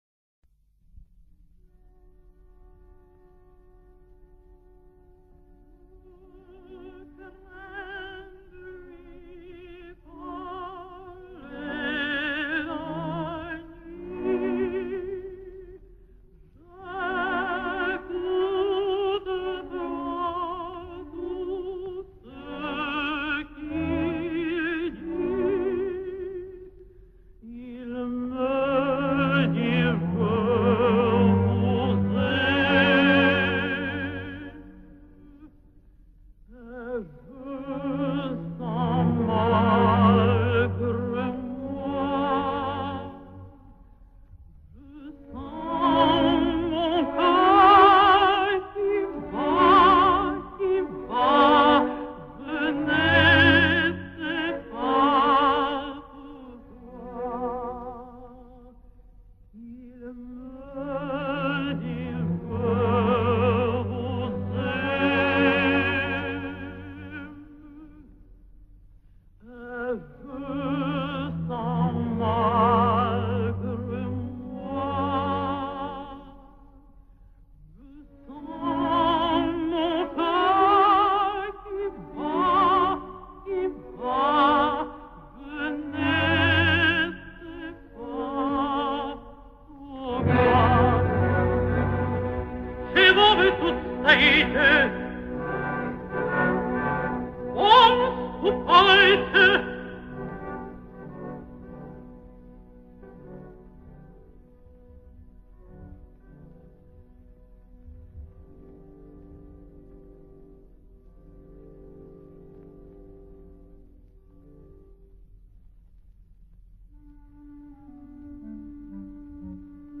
Обладала сильным, красивым голосом мягкого тембра, с «бархатным» контральтовым регистром и сопрановыми верхними нотами, богатством вокальных красок, драматической экспрессией, что позволяло ей с равным успехом исполнять партии контральто и меццо-сопрано.
Оркестр Большого театра. Дирижёр С. А. Самосуд. Исполняет Ф. С. Петрова.